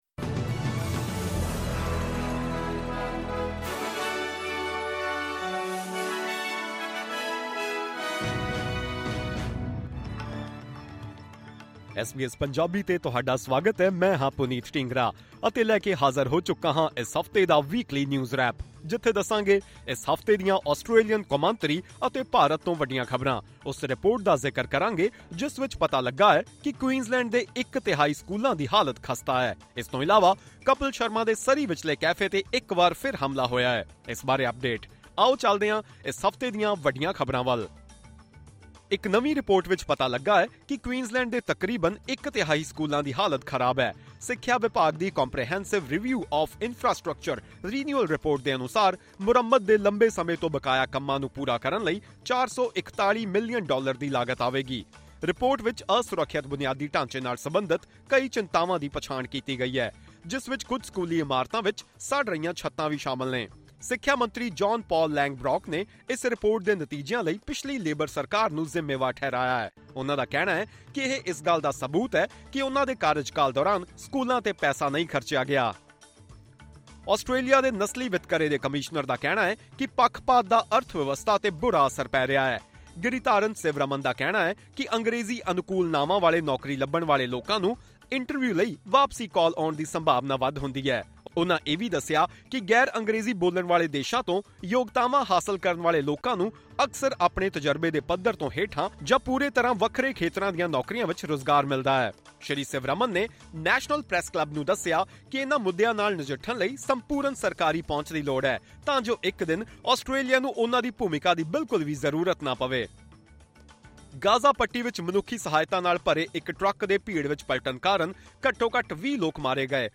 Top news of the week in Punjabi.